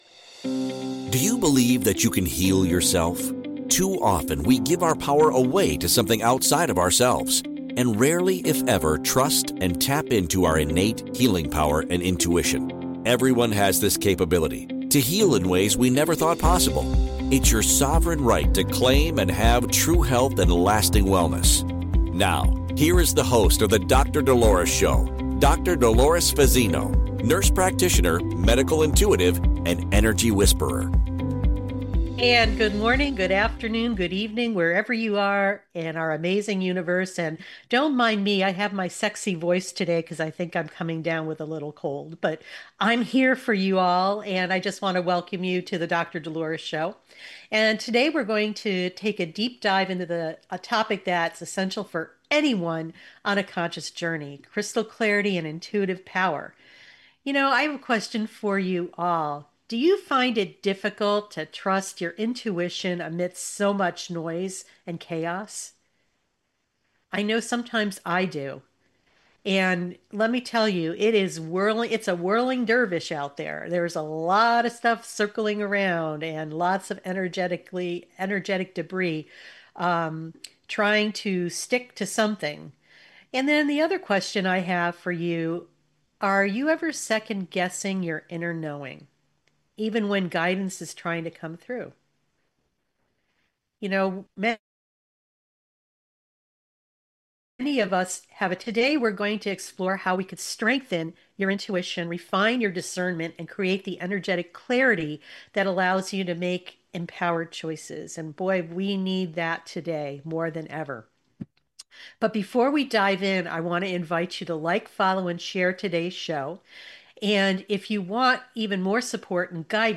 1 From Toxin Awareness to Hormone Harmony: A Clean Living Conversation 49:06